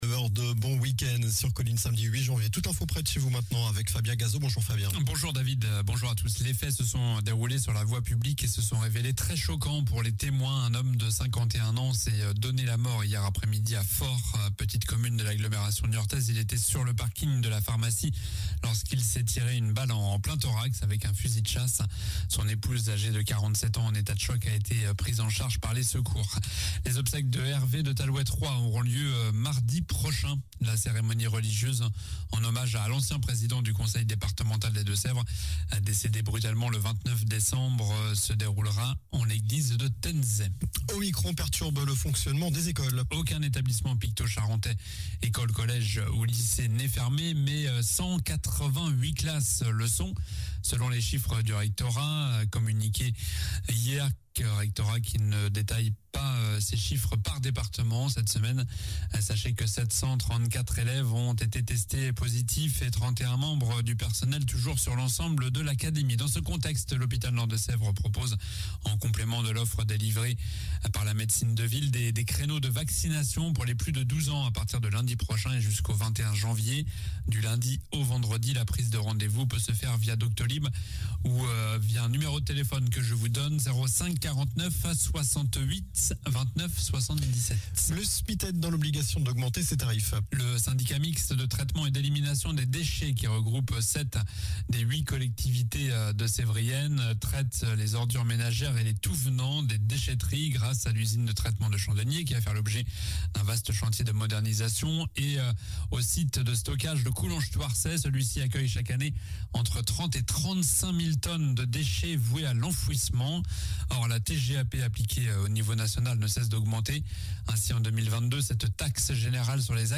Journal du samedi 08 janvier (matin)